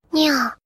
Nya Sound Effect Free Download
Nya